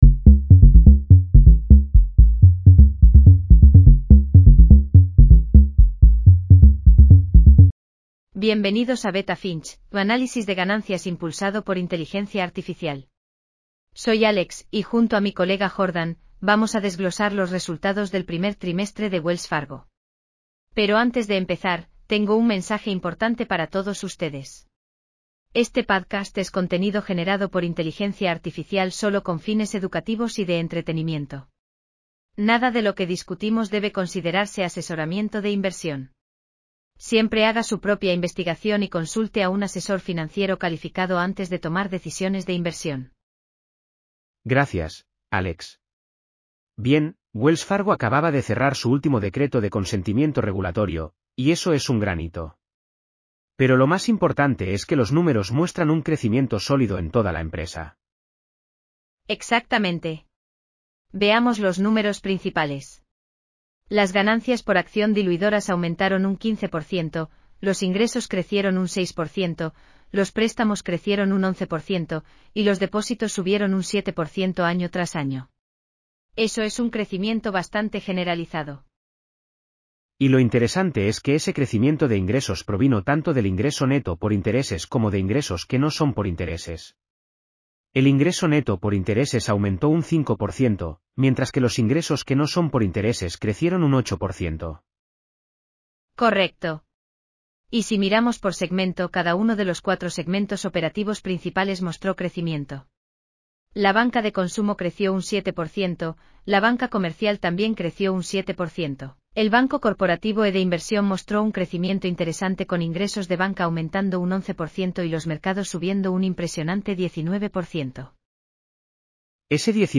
Wells Fargo Q1 2026 earnings call breakdown. Full transcript & podcast. 11 min. 5 languages.